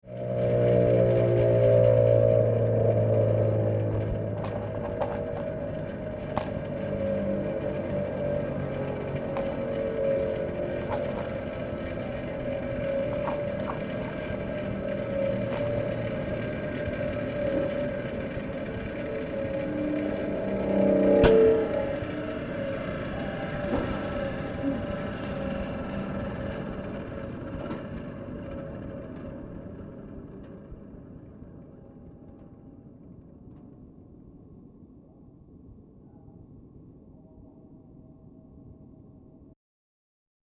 These sounds are primarily industrial / noise type sounds.
sound 8 40 sec. mono 397k